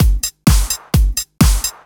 Electrohouse Loop 128 BPM (40).wav